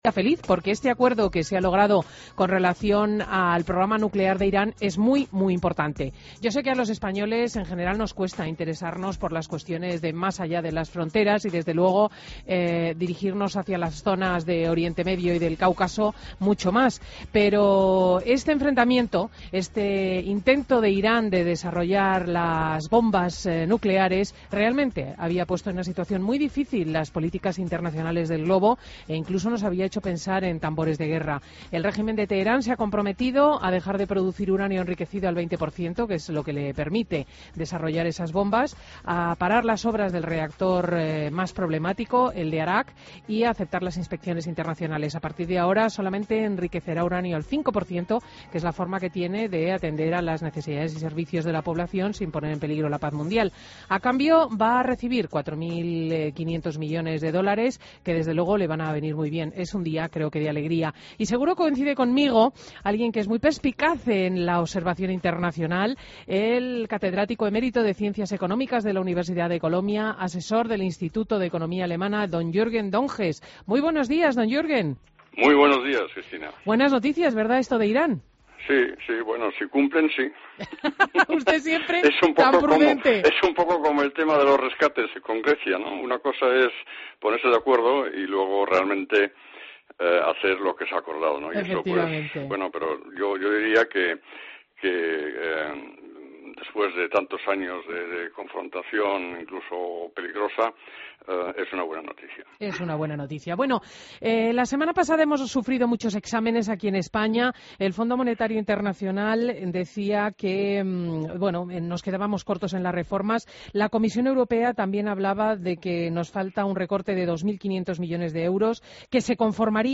Entrevista a Juerguen Donges en Fin de Semana COPE